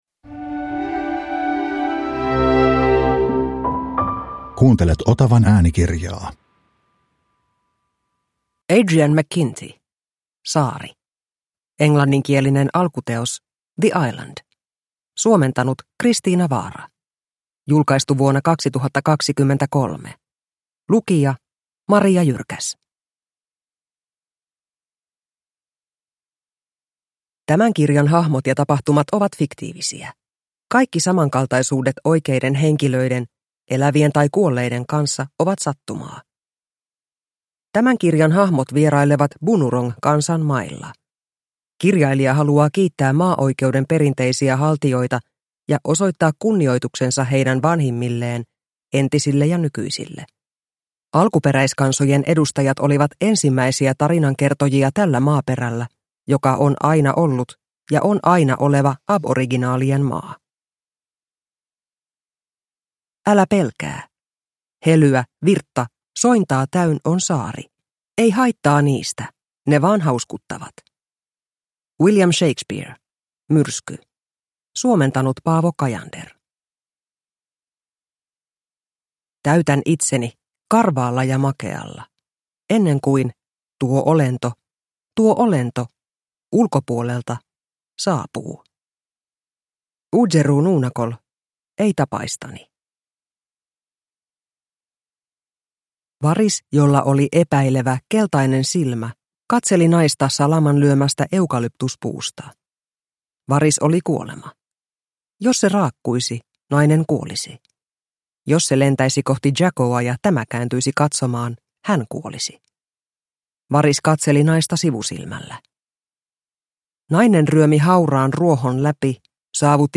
Saari – Ljudbok – Laddas ner